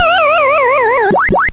pacman_death.mp3